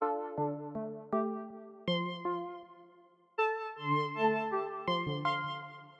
描述：巨大的
标签： 160 bpm Trap Loops Synth Loops 1.01 MB wav Key : G FL Studio
声道立体声